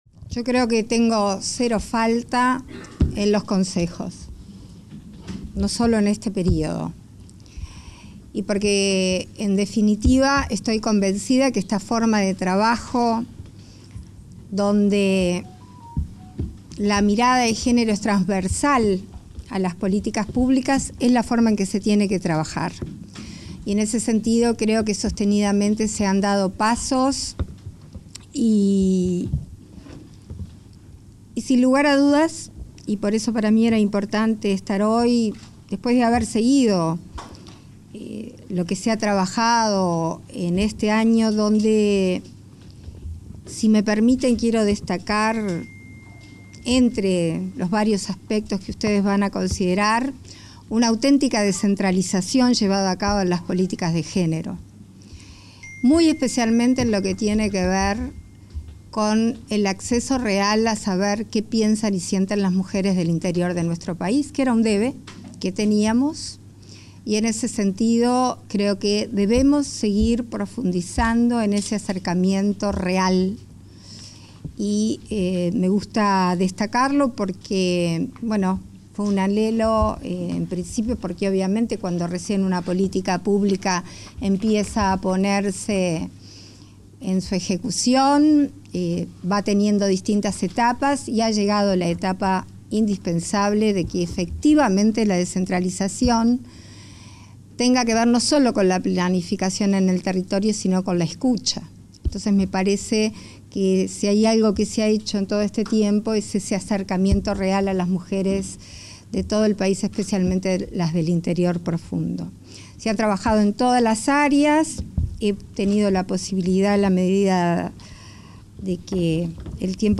Palabras de autoridades en reunión de Comisión Nacional de Género
Palabras de autoridades en reunión de Comisión Nacional de Género 28/10/2022 Compartir Facebook X Copiar enlace WhatsApp LinkedIn Este viernes 28, la presidenta en ejercicio, Beatriz Argimón, el ministro Martín Lema y la directora del Inmujeres, Mónica Bottero, participaron, en la Torre Ejecutiva, en la reunión del Consejo Nacional de Género.